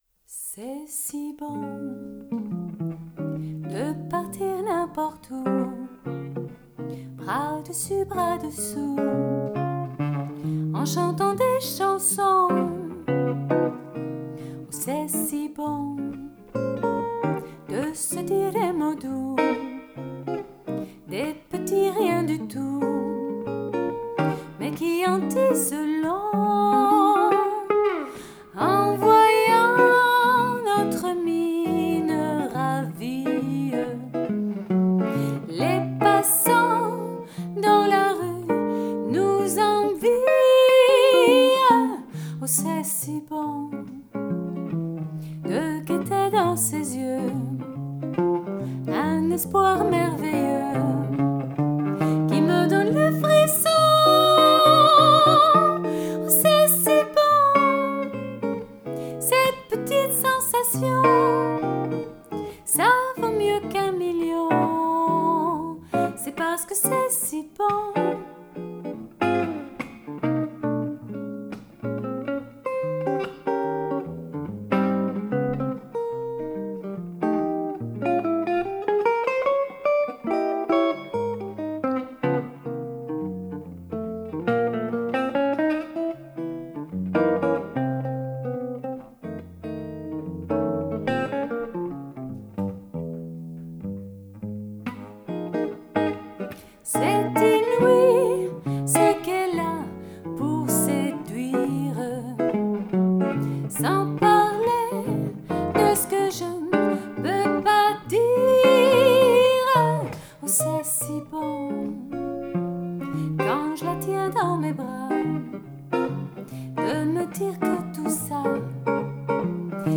Más que chançon…